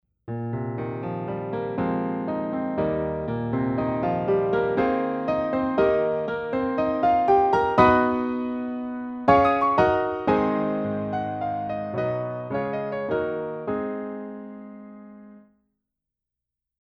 これらのピアノを32bit、ループなしで、贅沢に余すことなく収録したのがこのプラグインです。
最もベーシックな音と思われる一番上のプリセットを調整することなくそのまま使って、3台のピアノの音色を比べてみたいと思います。
・Bosendorfer 290 Grand
ベーゼンドルファーは重厚で、スタインウェイはやさしく温かみがあり、ヤマハはきらびやかな感じです。